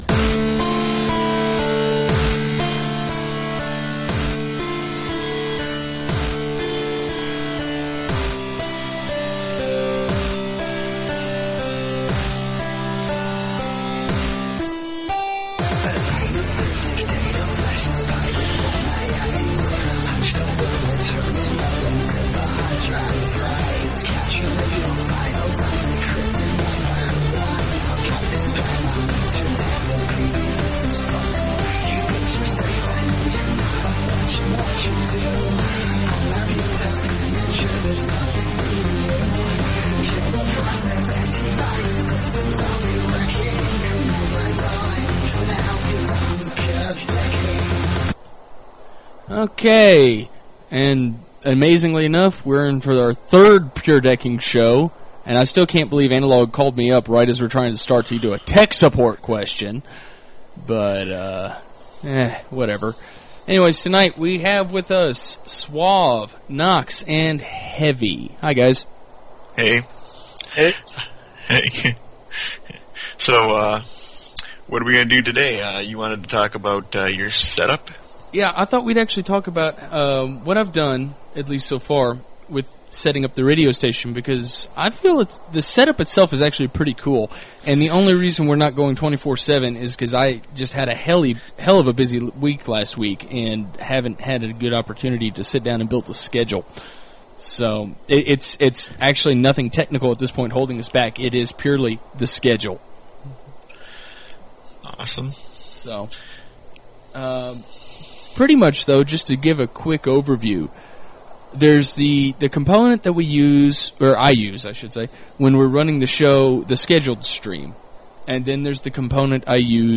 live show